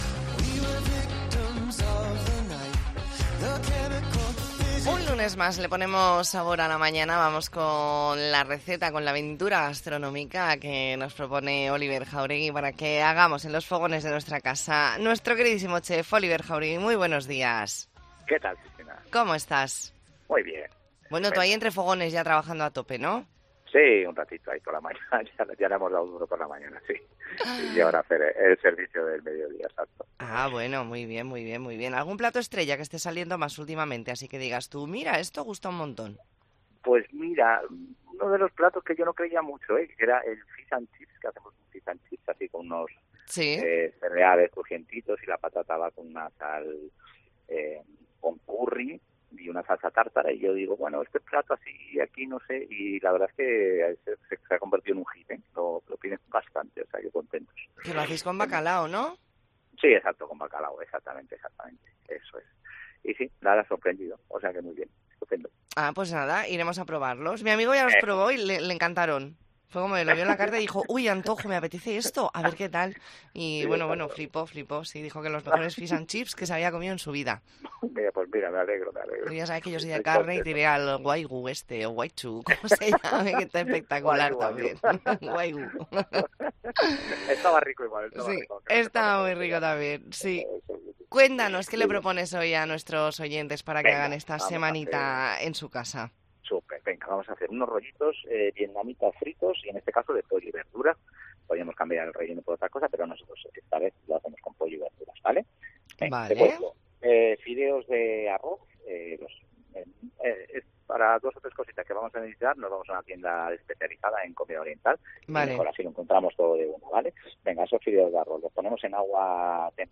Entrevista en La Mañana en COPE Más Mallorca, lunes 9 de octubre de 2023.